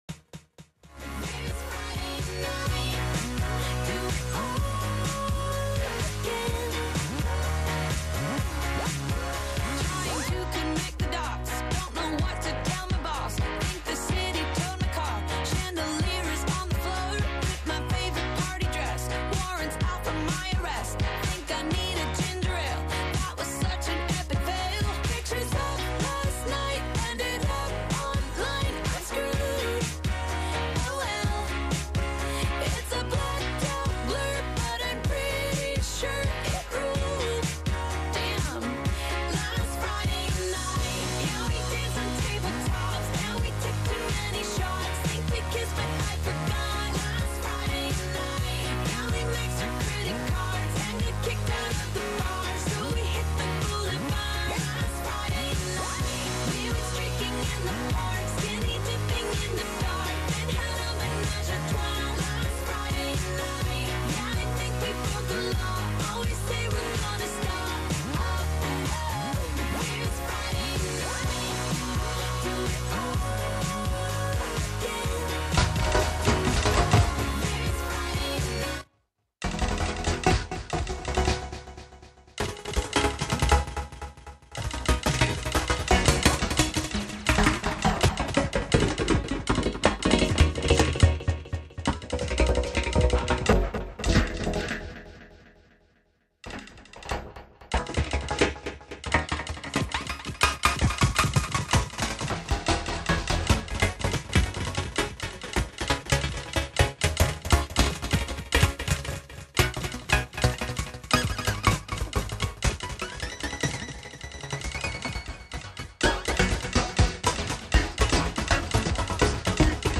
11am Live from Brooklyn, New York
making instant techno 90 percent of the time